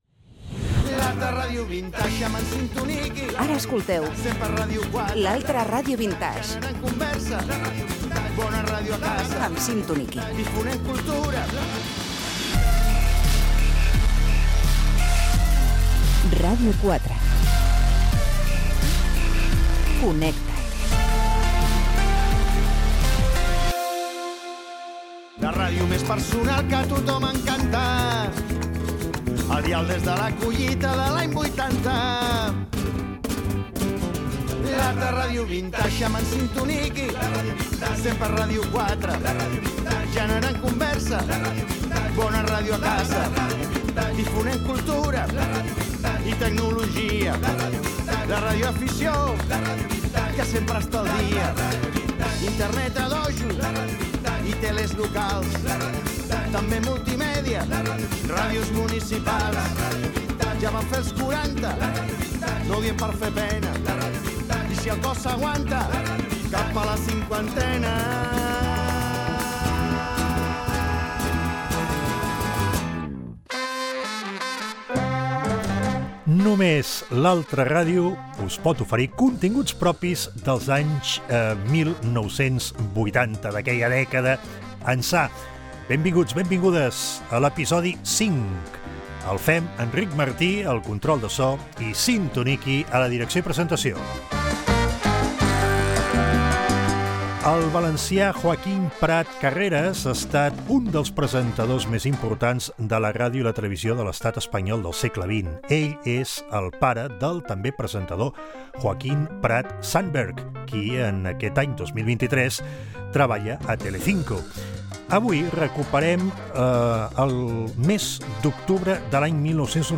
b704c4978b45aebdcdea106d21cac50a04681ec0.mp3 Títol Ràdio 4 Emissora Ràdio 4 Cadena RNE Titularitat Pública estatal Nom programa L'altra ràdio vintage Descripció Sintonia del programa, presentació del cinquè episodi, entrevista al presentador Joaquín Prat quan presentava “Apúntate 5” a Ràdio Nacional d’Espanya i el concurs de televisió “El precio justo”.